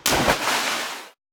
drop sound.
drop.wav